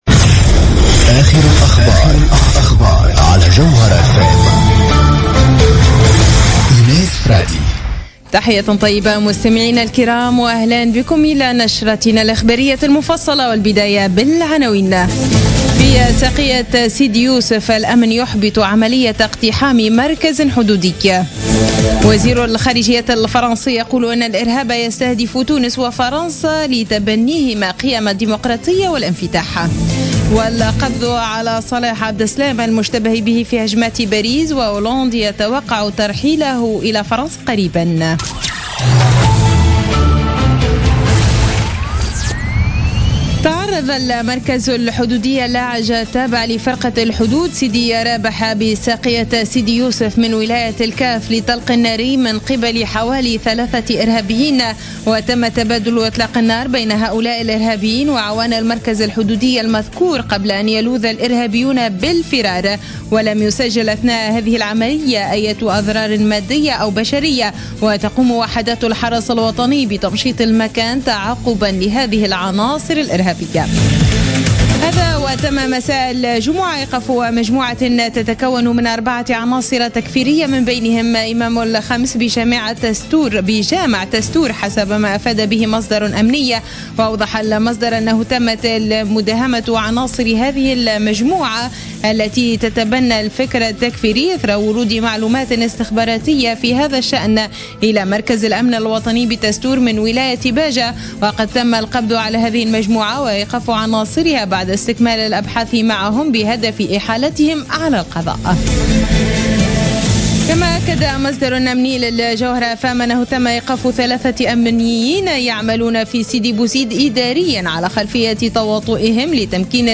نشرة أخبار منتصف الليل ً ليوم السبت 19 مارس 2016